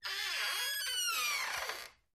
Creak
Door Open Close / Squeaks, Various; Door Squeaks 3